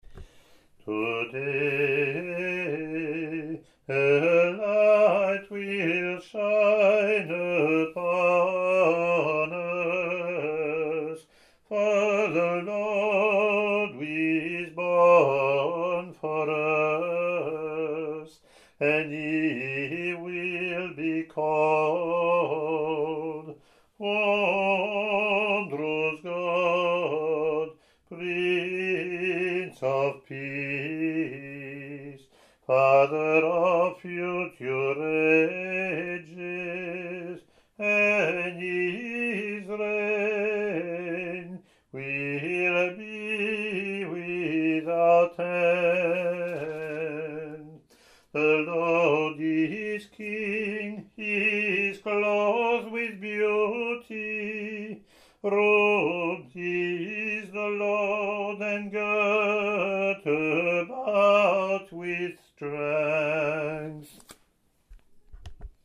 Latin antiphon)
Alternate Introit, same as Christmas Mass at Dawn: